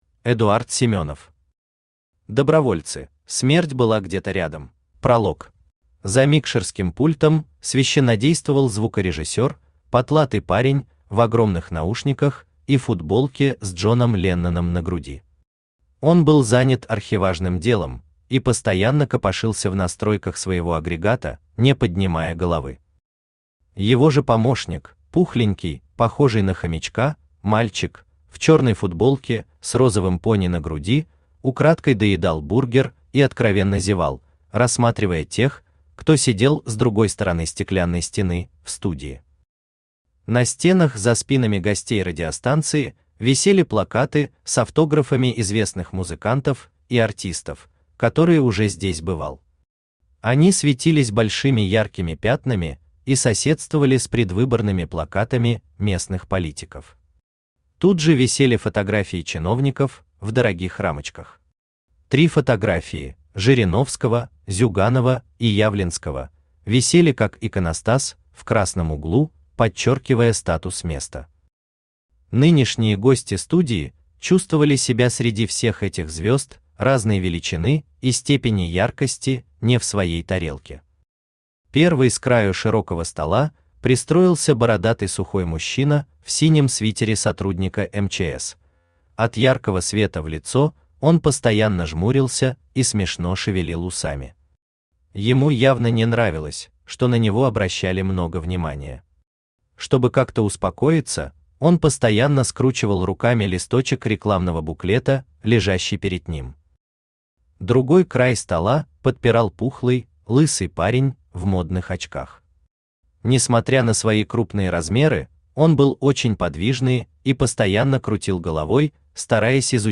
Автор Эдуард Евгеньевич Семенов Читает аудиокнигу Авточтец ЛитРес.